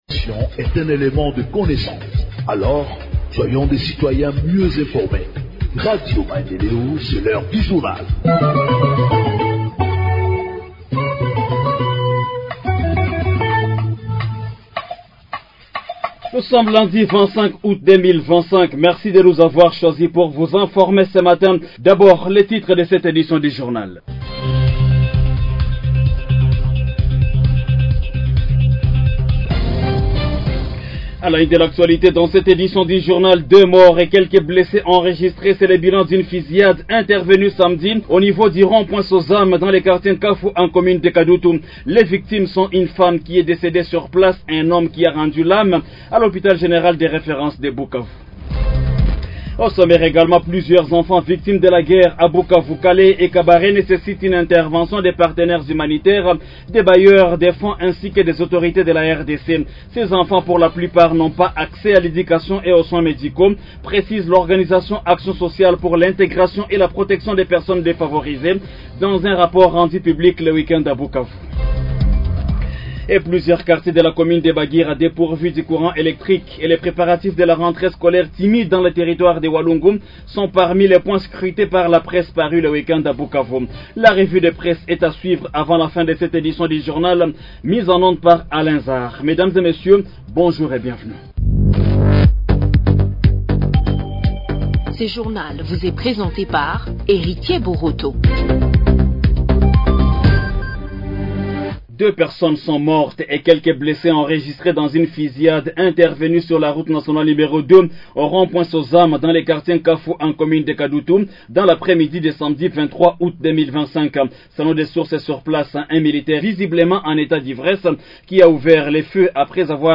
Journal en Français du 25 Aout 2025 – Radio Maendeleo